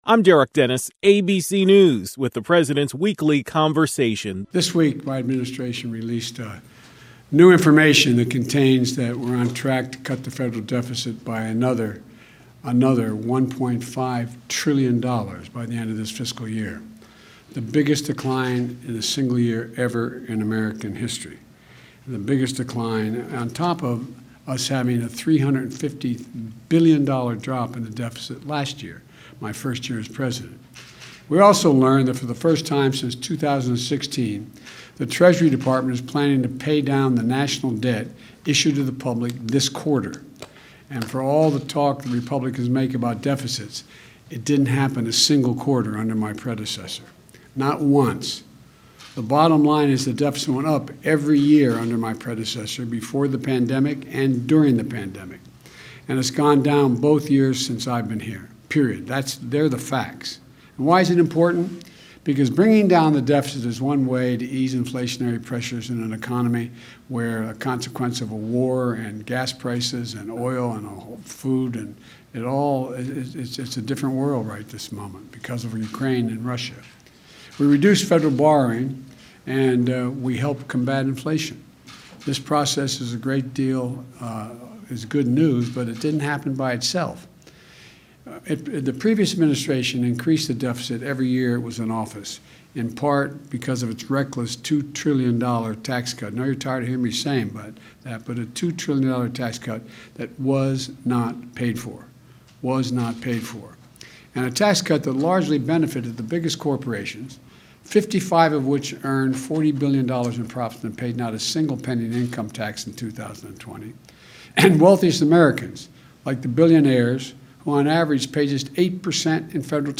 President Biden spoke on economic growth, jobs, and deficit reduction.